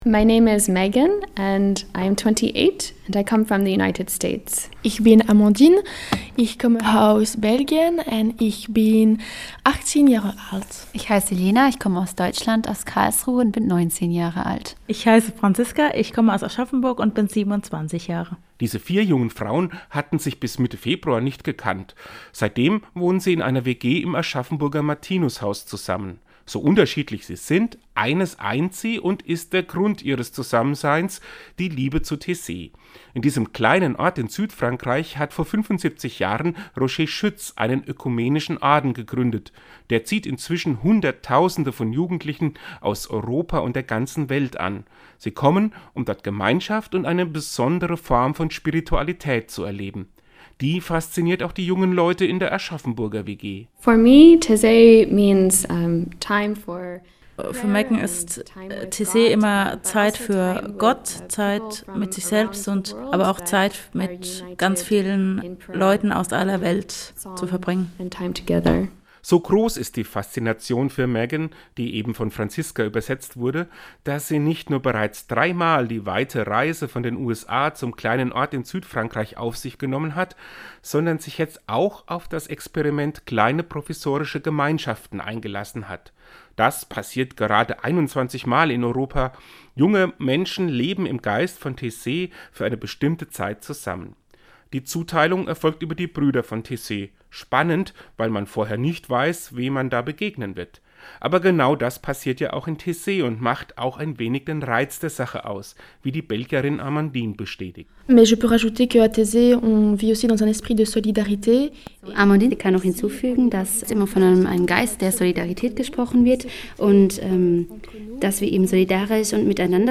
WG besucht Seinen Radiobeitrag können Sie unten Downloaden!